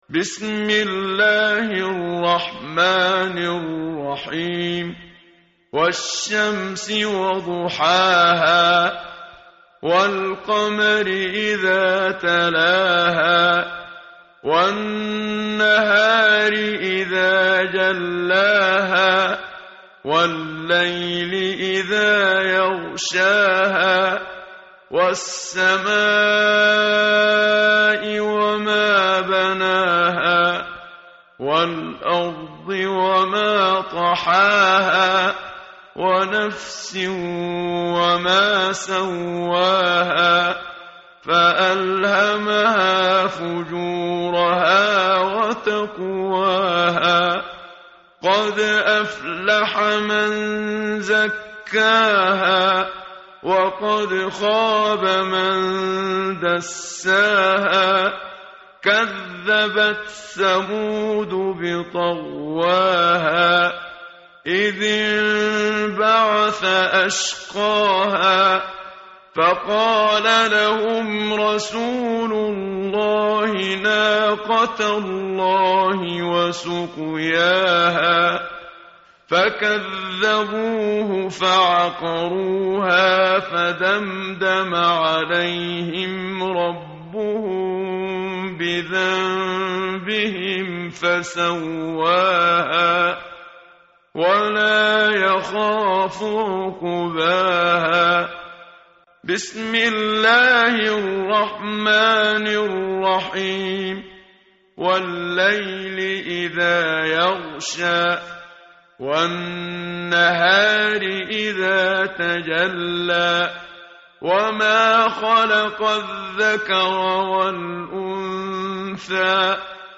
tartil_menshavi_page_595.mp3